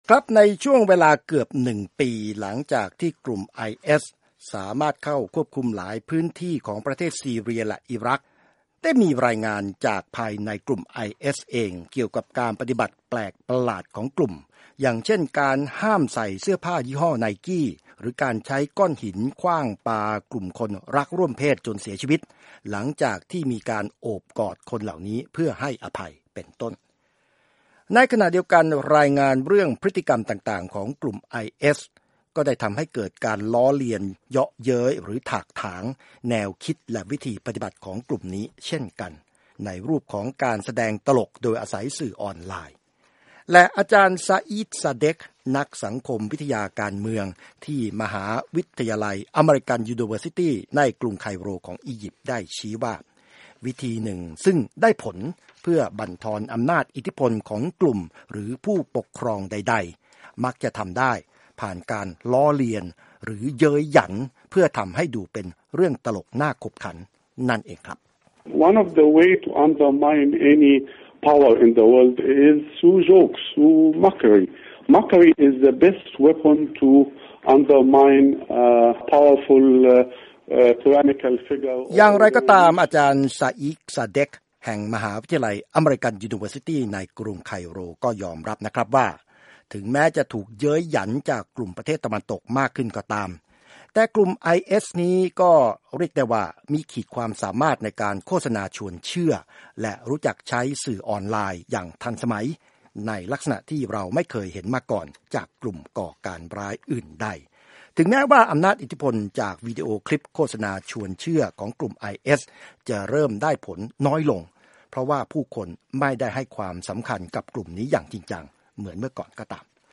ISIS Report